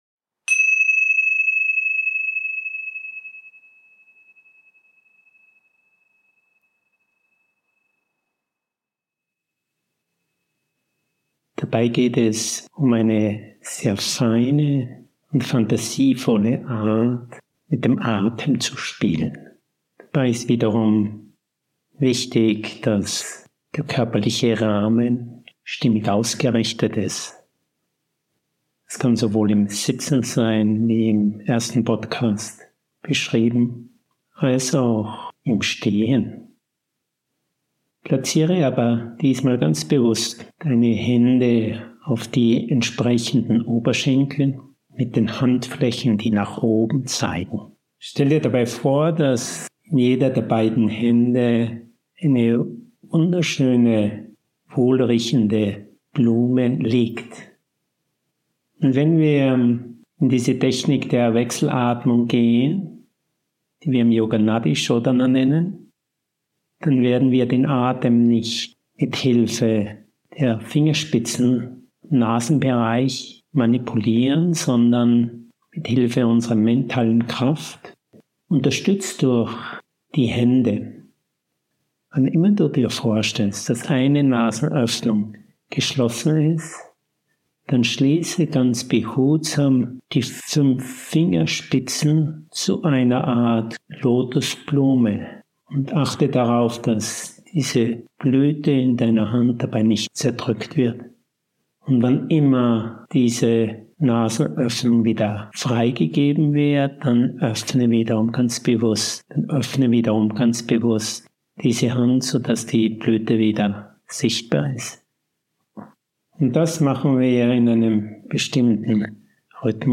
Genre: Meditation.